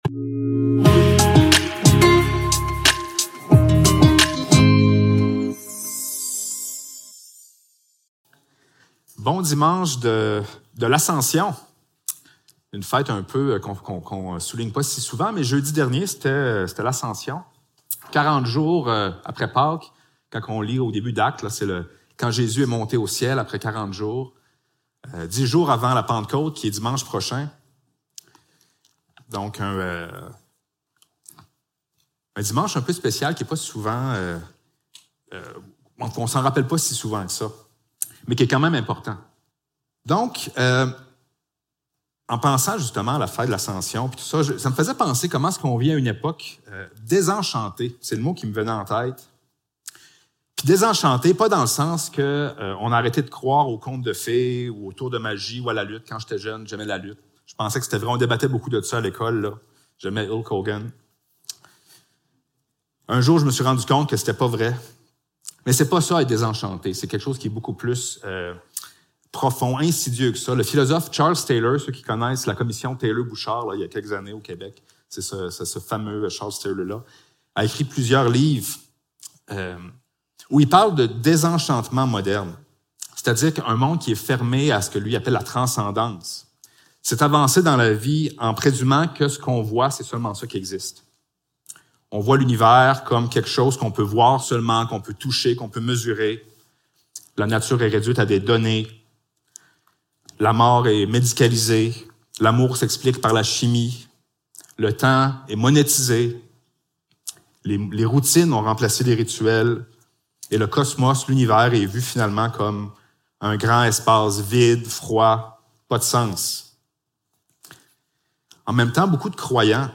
Psaume 110 Service Type: Célébration dimanche matin Description